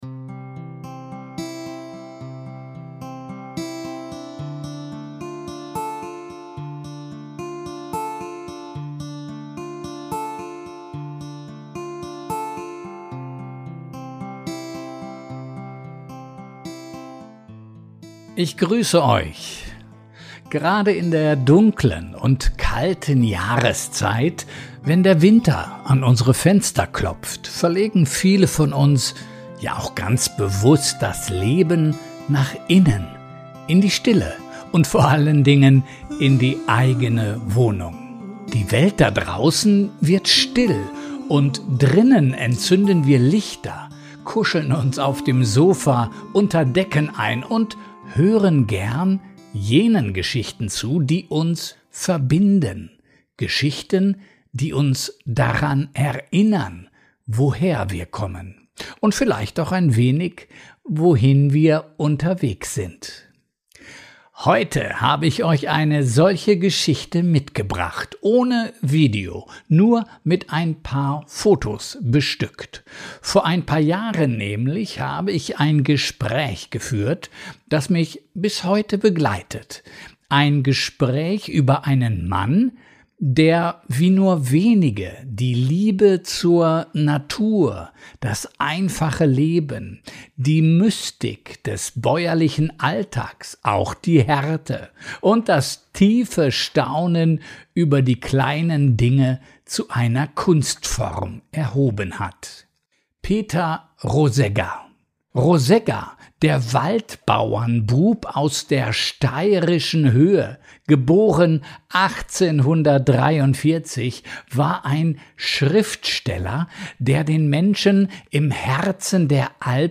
Ein stilles Wintergespräch über Heimat, Natur, bäuerliche Lebensklugheit – und über jene Wärme, die aus Worten entsteht, wenn sie aus echter Erfahrung geboren sind.